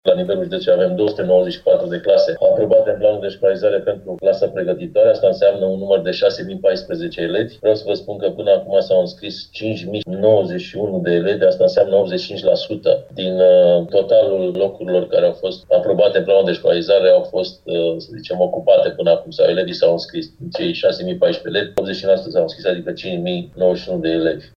Până acum, în prima etapă de înscriere, care se va încheia în 28 aprilie, au fost ocupate, 85% din locurile puse la dispoziție în Timiș, anunță șeful Inspectoratului Școlar, Marin Popescu.